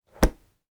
Book Close.wav